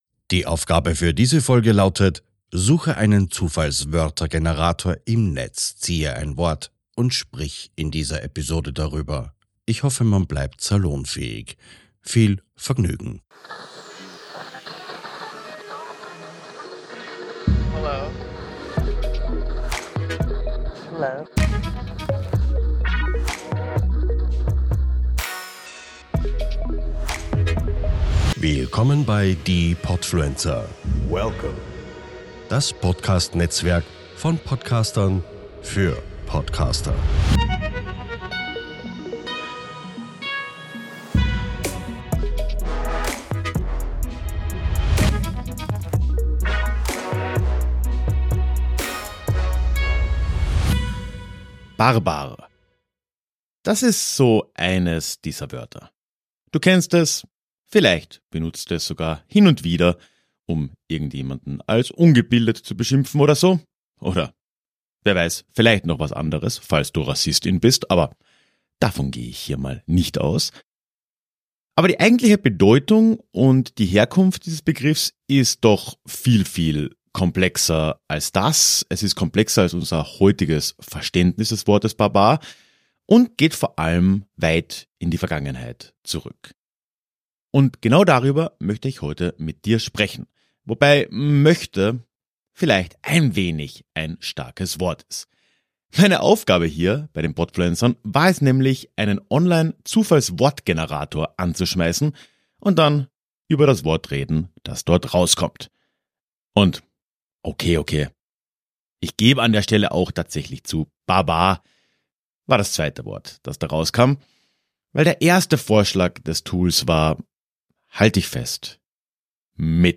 Intro-Sprecher